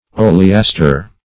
Oleaster \O`le*as"ter\, n. [L., fr. olea olive tree.